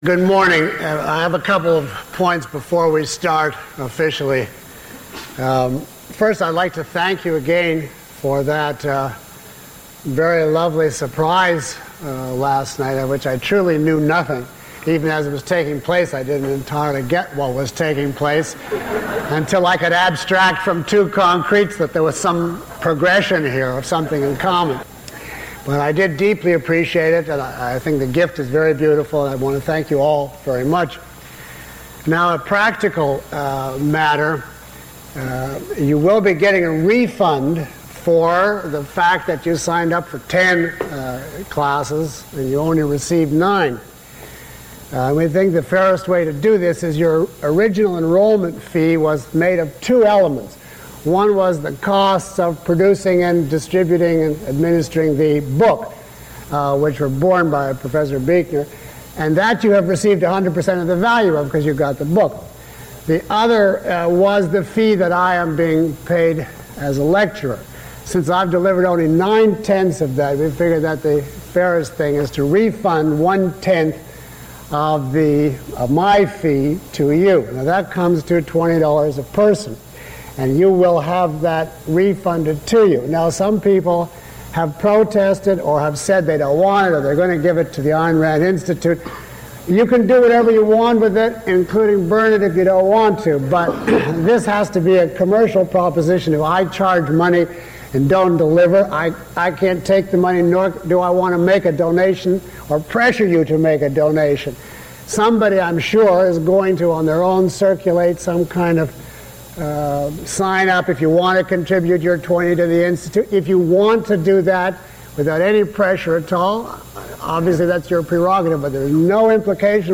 Peikoff used these seminars to discuss what he learned in the process and to demonstrate how it would allow students of Objectivism to gain a new understanding of the philosophy.